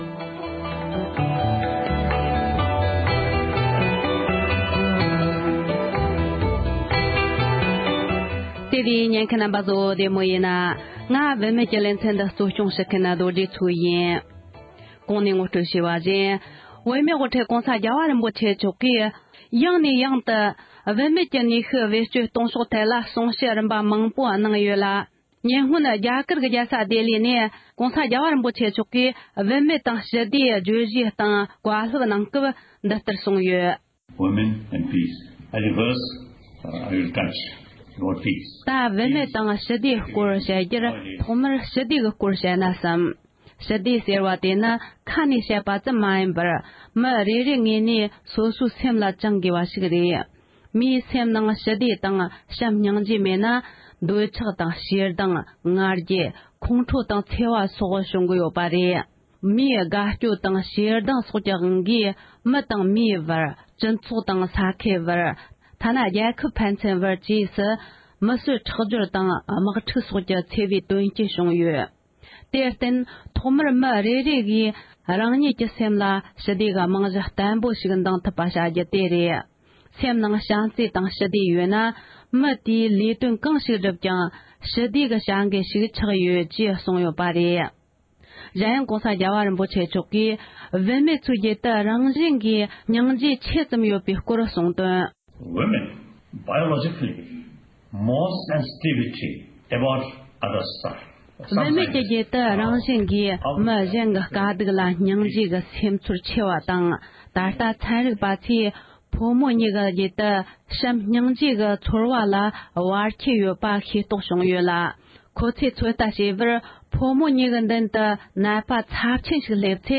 བུད་མེད་ཀྱི་ནུས་ཤུཊ་སྤྱི་ཚོཊ་ཐོག་བེད་སྤྱོད་གཏོང་ཕྱོཊ་དང་འབྲེལ་བའི་ཐད་༸གོང་ས་མཆོག་གི་བཀའ་སློབ་དང་མི་སྣ་ཁག་གི་གསུང་བཤད།